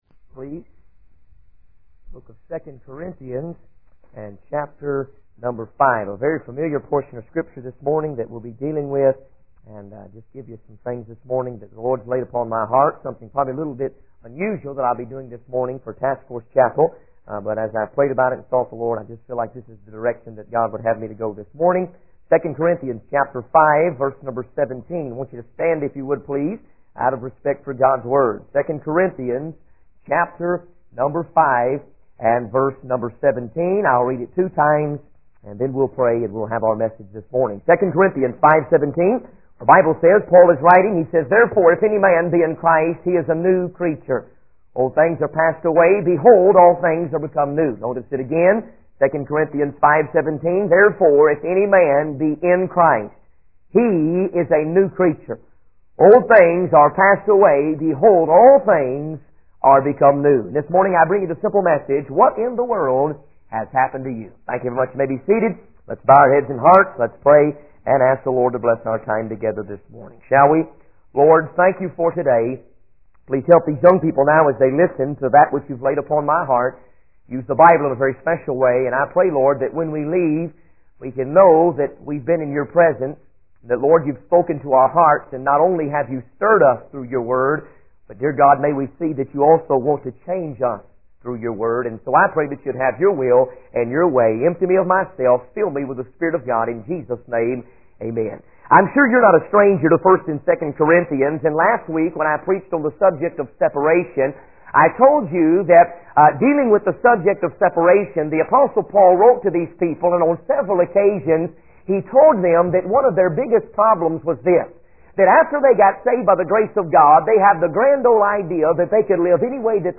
In this sermon, the speaker invites a man to share his testimony and plans for the mission field.